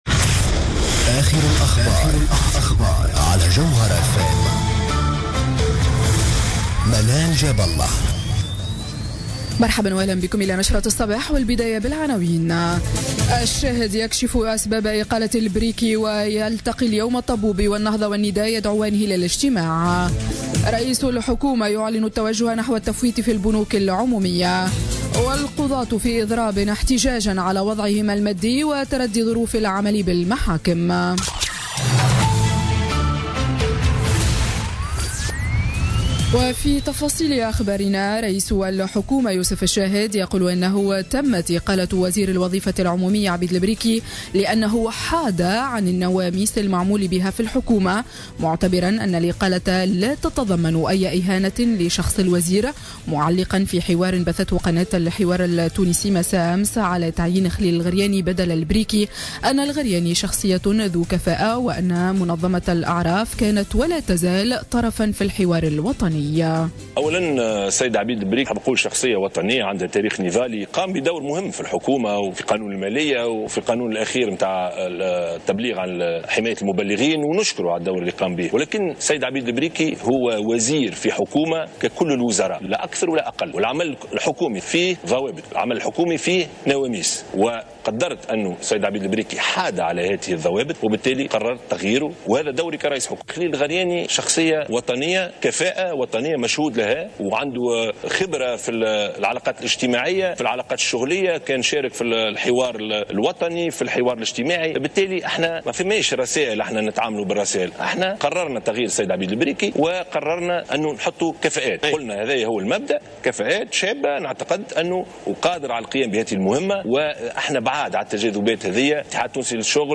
نشرة أخبار السابعة صباحا ليوم الاثنين 27 فيفري 2017